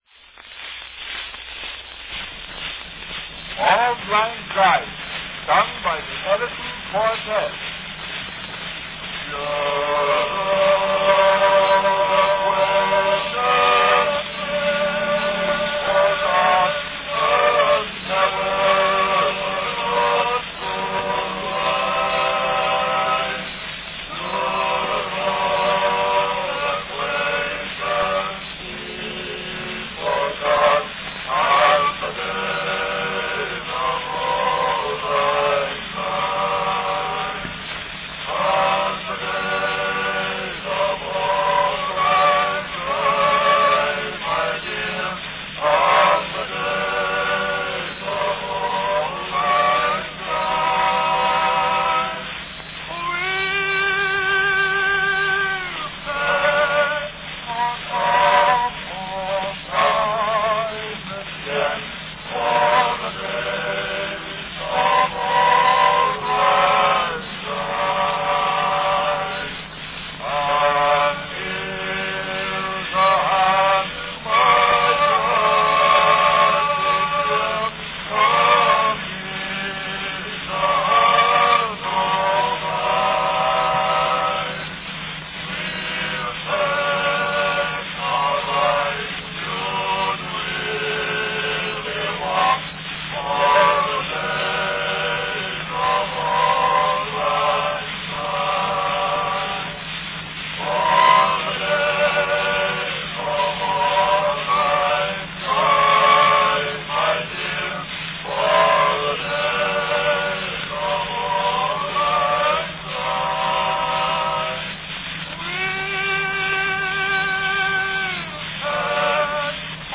From 1898, a classic song of the Season, Auld Lang Syne, sung by the Edison Male Quartette.
Category Quartette
Performed by Edison Male Quartette
Announcement "Auld Lang Syne, sung by Edison Quartette."
The background rumbling sound comes from a mechanical process used, probably not at Edison's Labs but at a record dealership, in duplicating this record from a master cylinder.